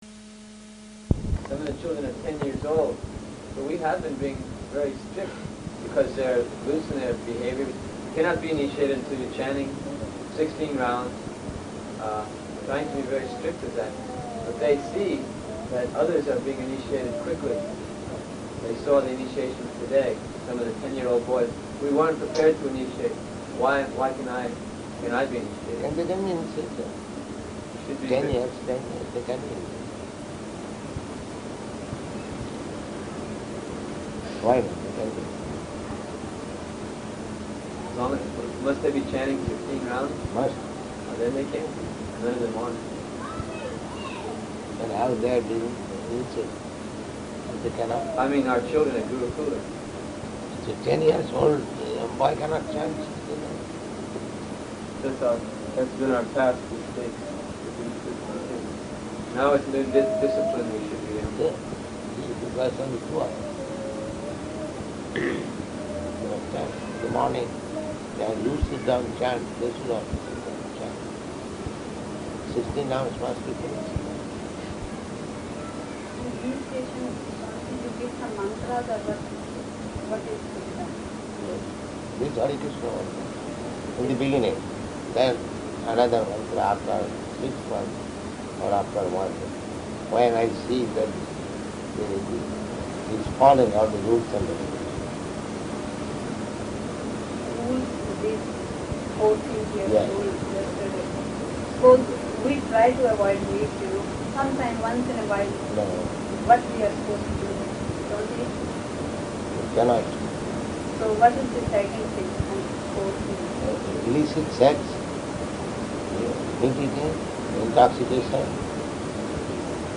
Room Conversation
Type: Conversation
Location: Dallas